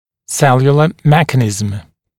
[‘seljulə ‘mekənɪzəm][‘сэлйулэ ‘мэкэнизэм]клеточный механизм